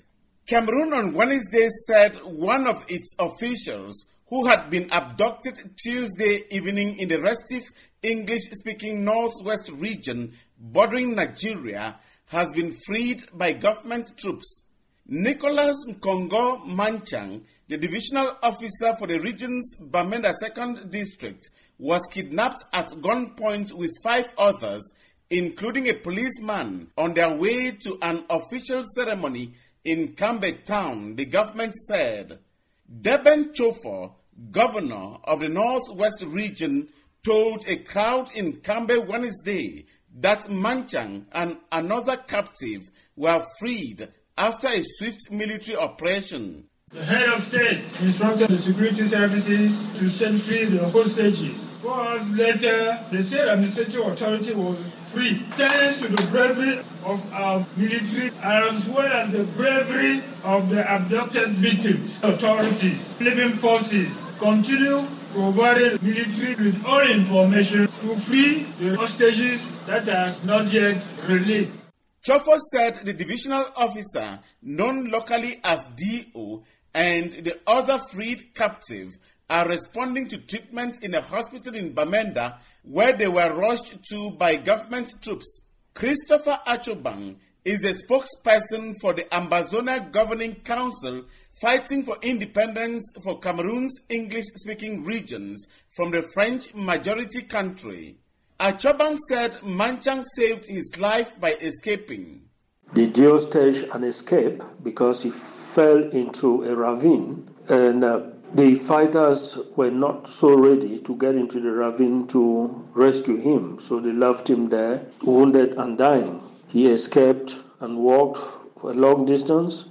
reports from Yaounde that the abduction and killing followed renewed separatist attacks that have claimed several dozen civilians within two weeks